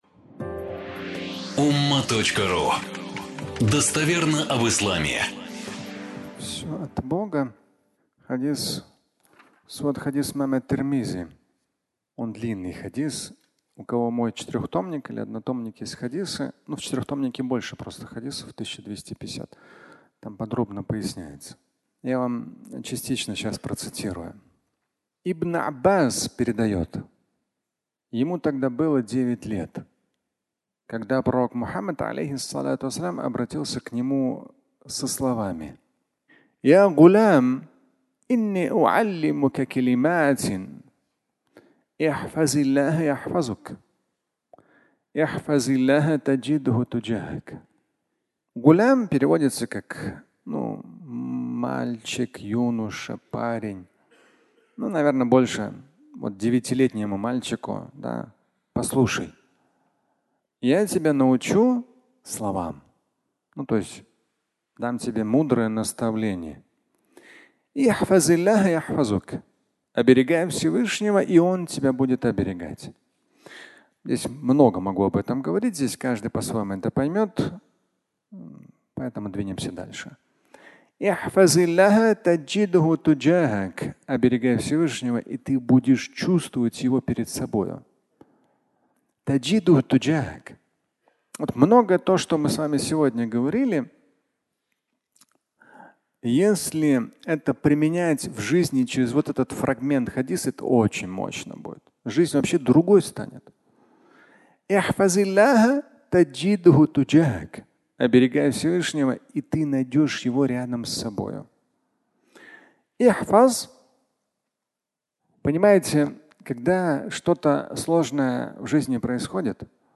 Всё от Бога (аудиолекция)